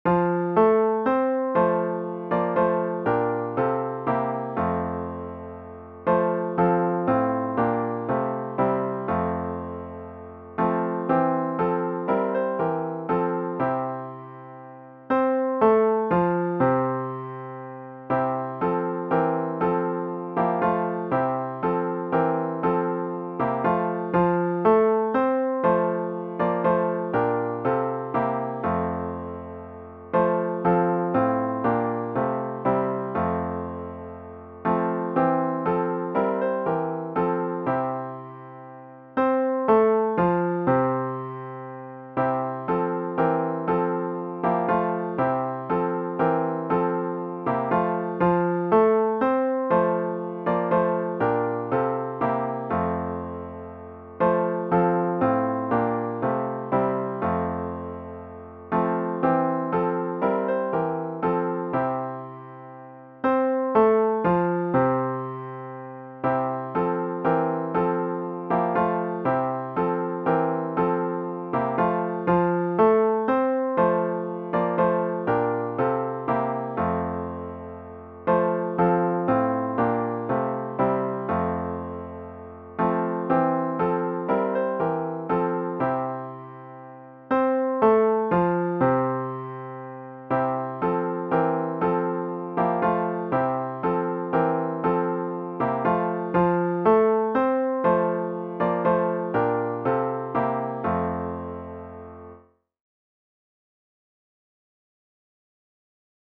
*OPENING HYMN “Come, Thou Almighty King” GtG 2  Words: Collection of Hymns for Social Worship, 1757, alt.